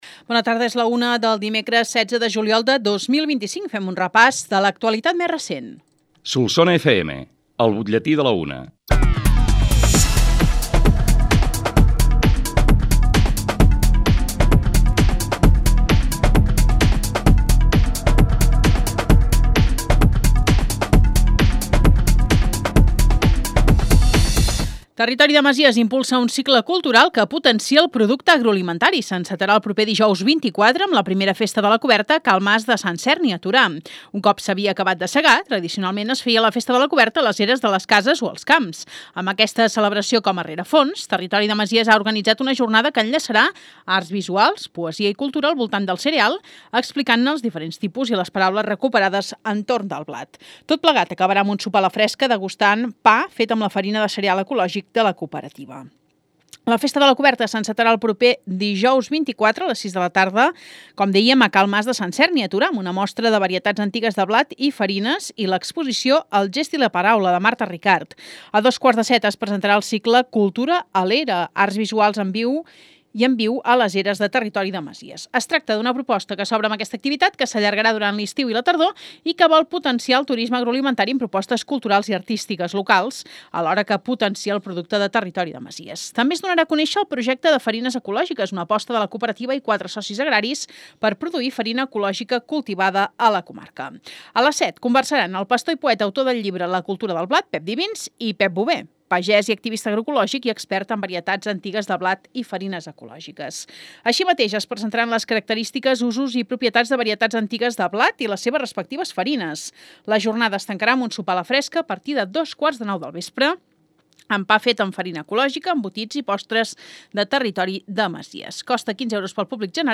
L’ÚLTIM BUTLLETÍ
BUTLLETI-16-JUL-25.mp3